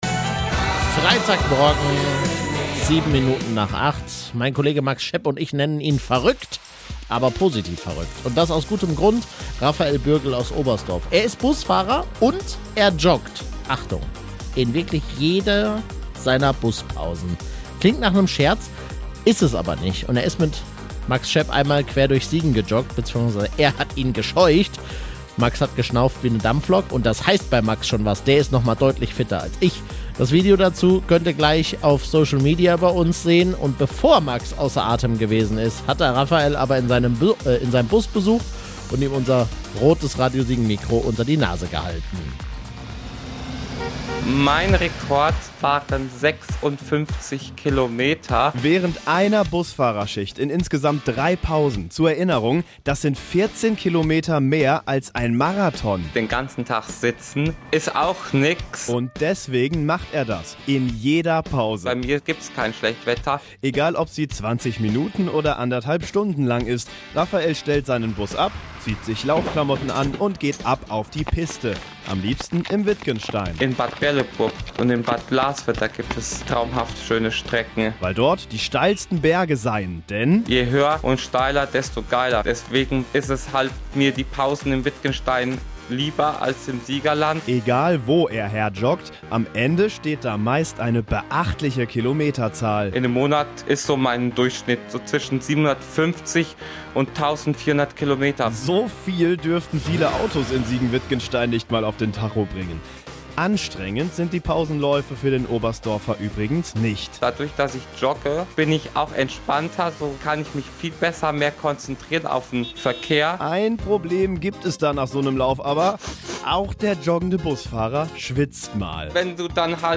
einmal quer durch Siegen gejoggt